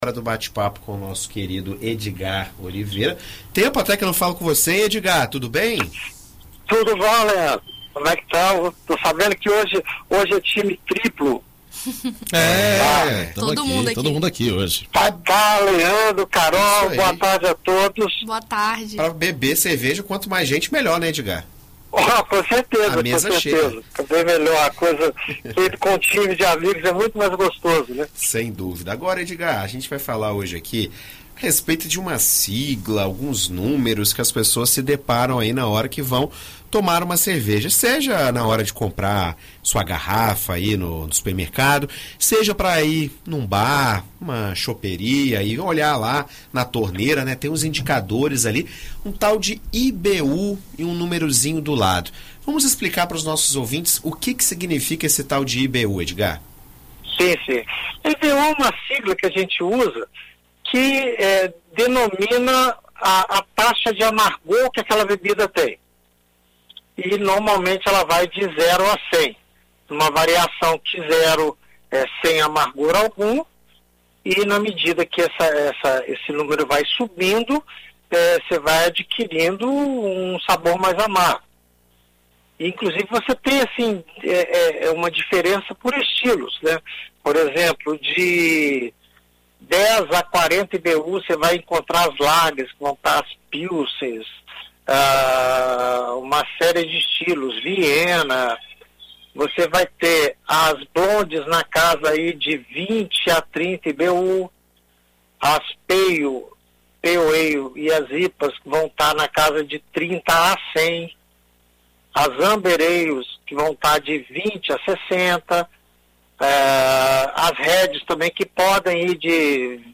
No comentário na BandNews FM Espírito Santo nesta sexta-feira